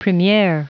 Prononciation du mot premiere en anglais (fichier audio)
Prononciation du mot : premiere